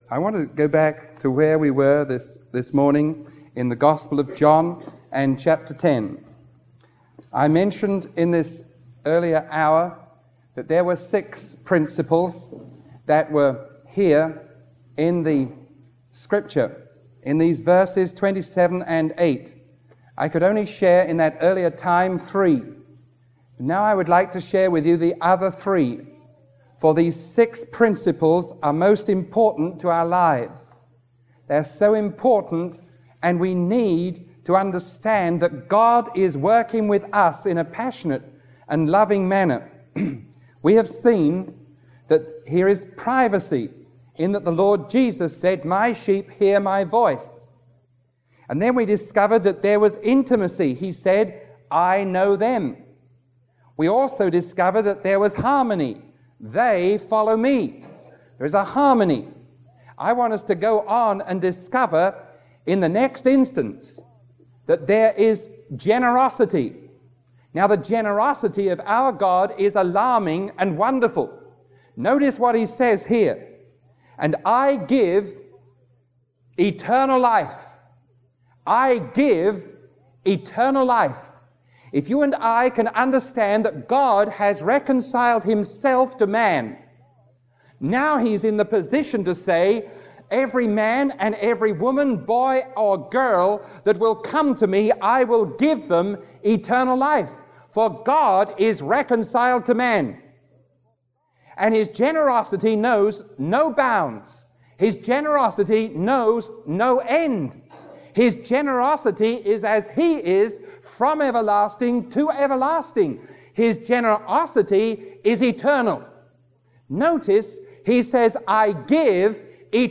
Sermon 0206A recorded on August 10